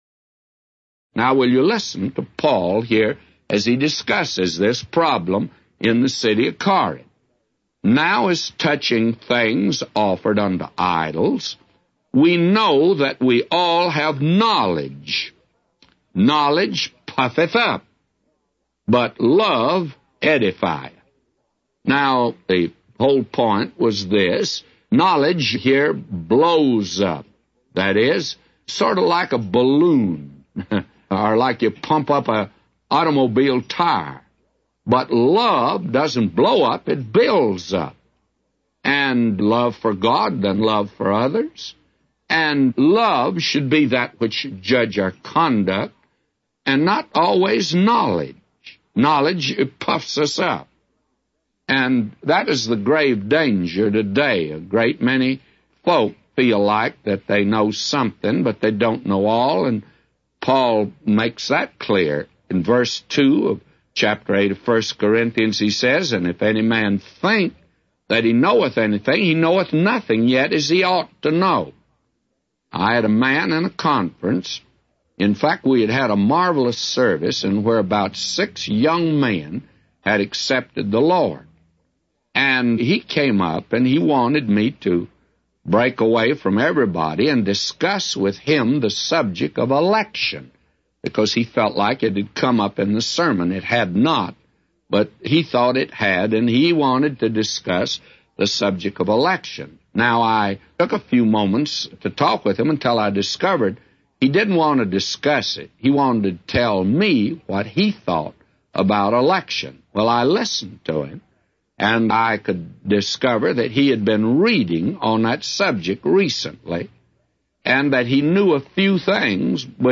A Commentary By J Vernon MCgee For 1 Corinthians 8:1-999